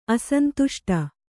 ♪ asantuṣṭa